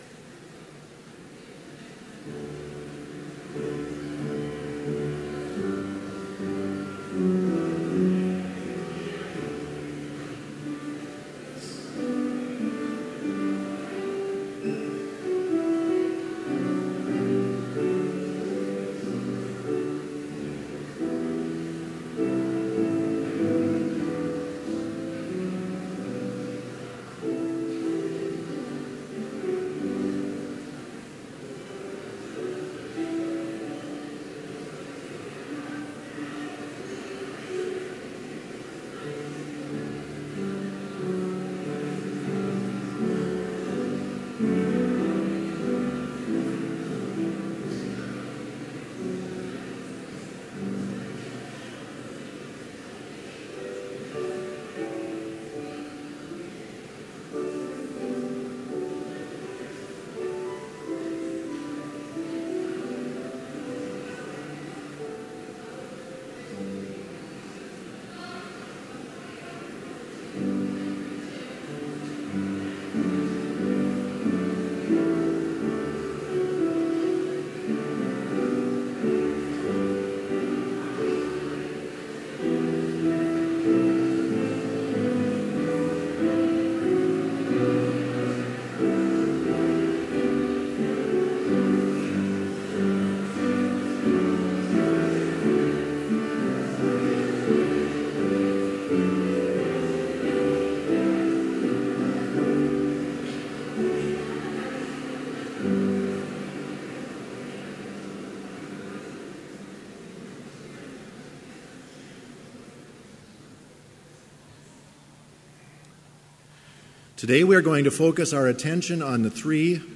Chapel worship service held on October 18
BLC Trinity Chapel, Mankato, Minnesota
Complete service audio for Chapel - October 18, 2016